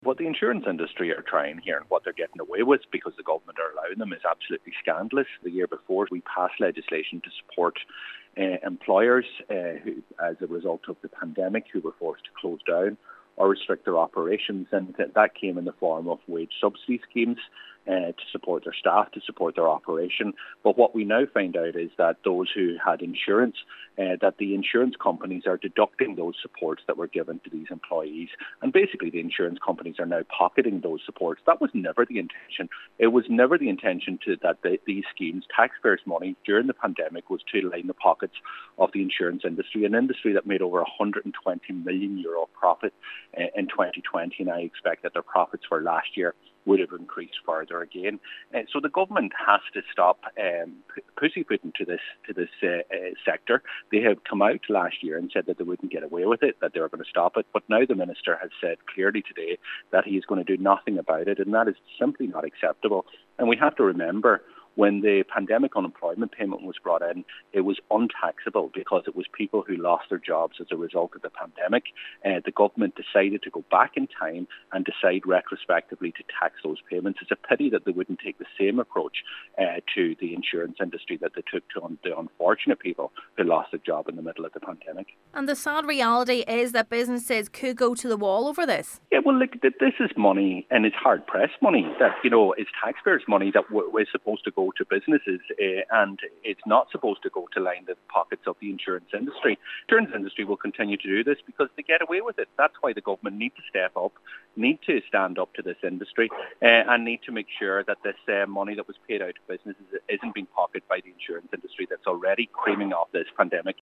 He says the intention of pandemic supports was not to line the pockets of the insurance industry: